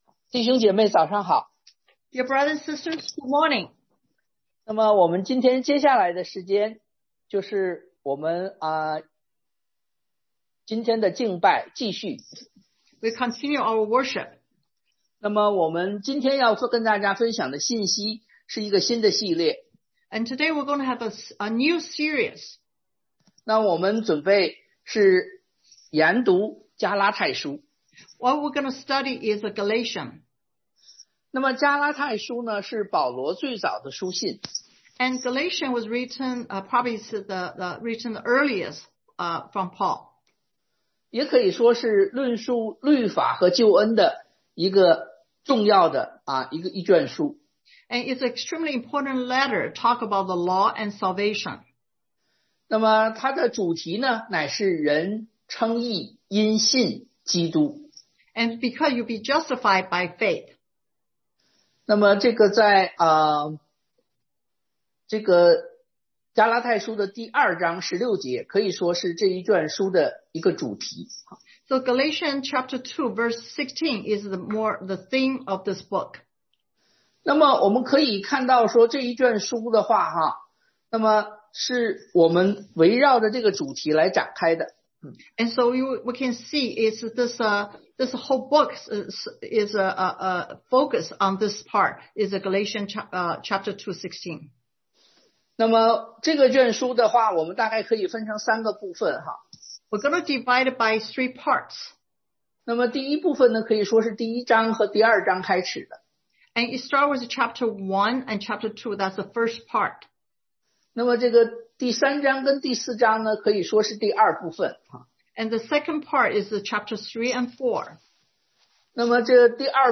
Gal 1:1-10 Service Type: Sunday AM 1.福音只有一個 There is only one gospel 2.福音關乎耶穌基督 It is the gospel of Jesus Christ 3.